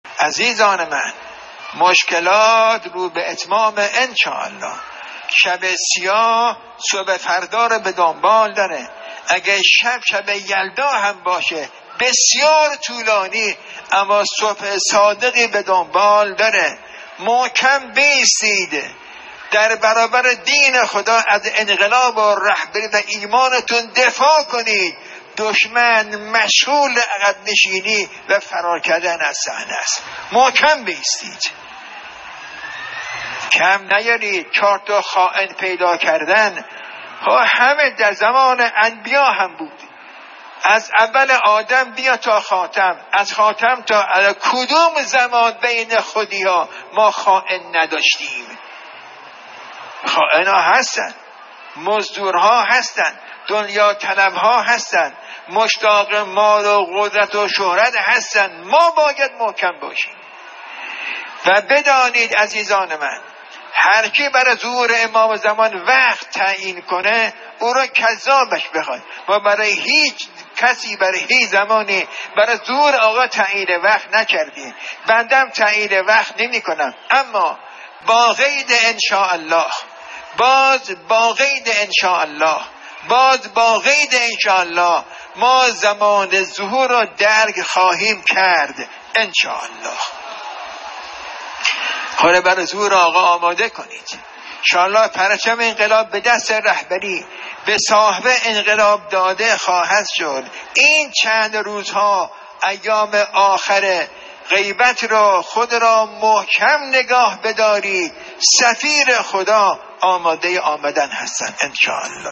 در یکی از سخنرانی های خود به موضوع «آمادگی برای ظهور در روزهای پایانی» اشاره کرد که تقدیم شما فرهیختگان می شود.